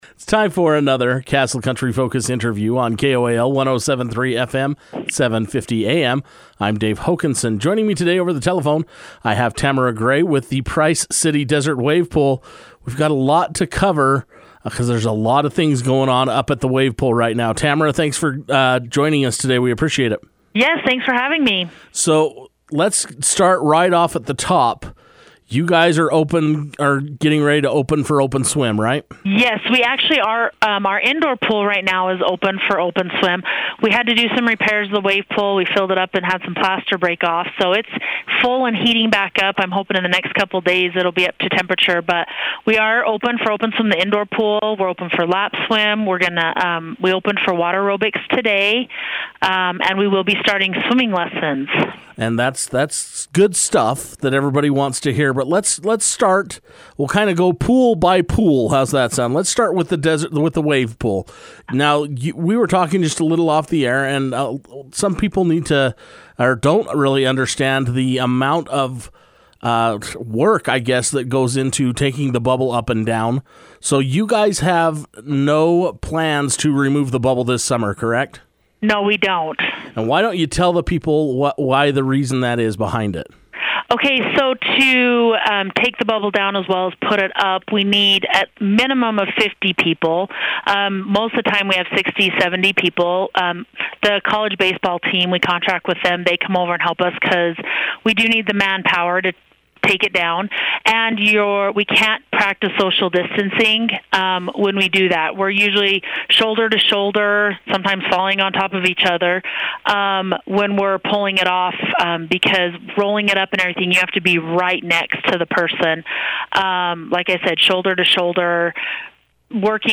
took time to speak over the telephone